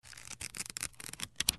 Звуки книги
7. Прокрутка страниц книги